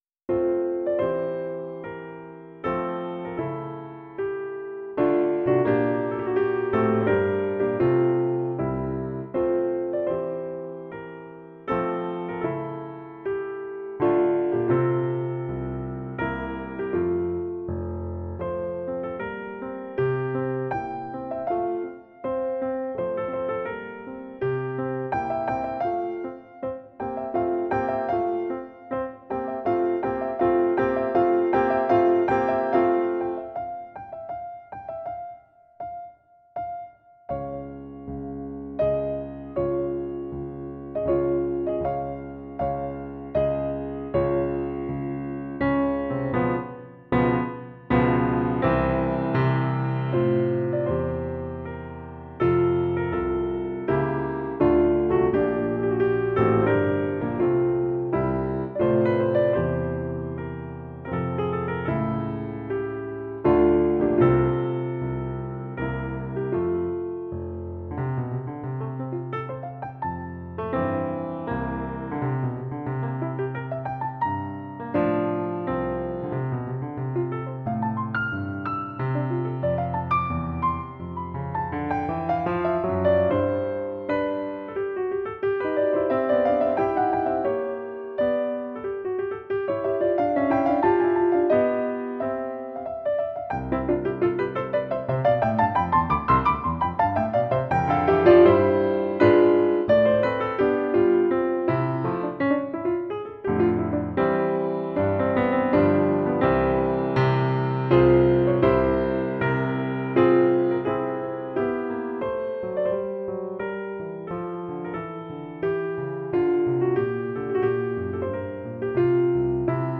No parts available for this pieces as it is for solo piano.
Instrument:
Classical (View more Classical Piano Music)